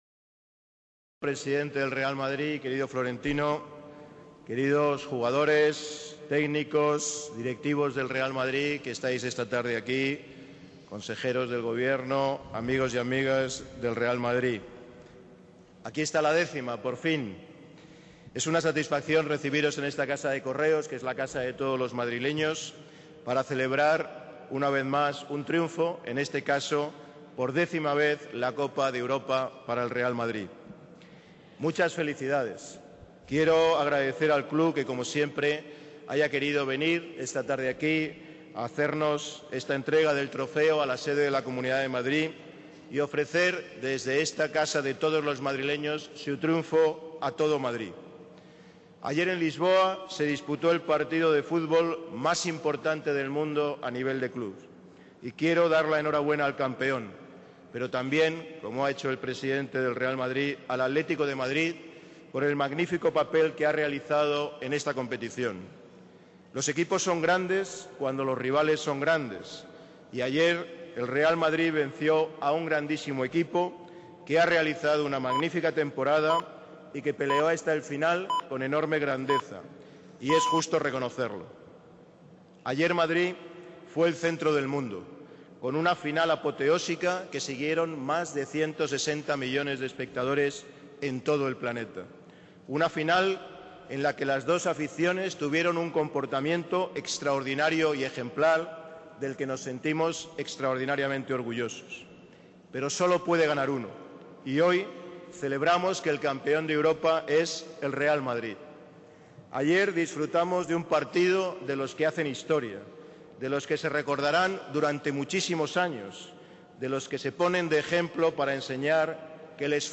Procedentes de las instalaciones del club en Valdebebas, los futbolistas y el presidente del Real Madrid, Florentino Pérez, han llegado a la Puerta del Sol, donde se ubica la sede de la presidencia de la Comunidad de Madrid y se han congregado esta tarde miles de aficionados para agasajar a los campeones de Europa.